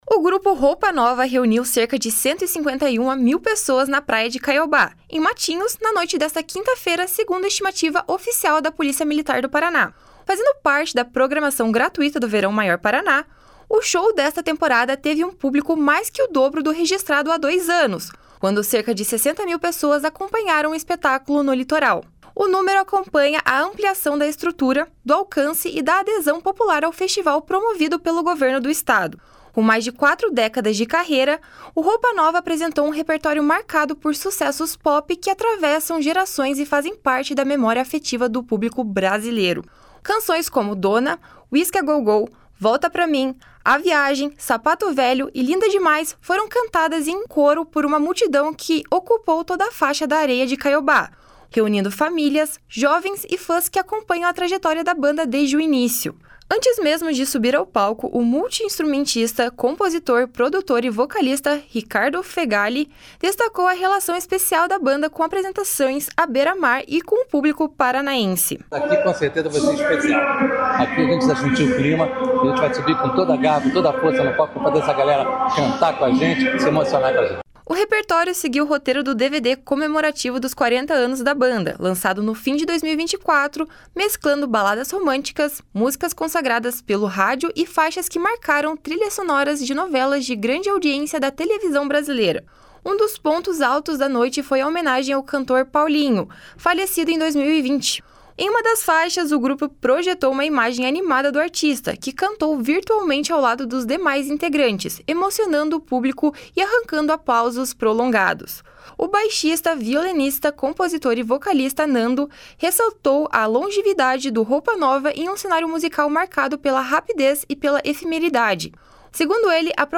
// SONORA RICARDO FEGHALI //
// SONORA NANDO //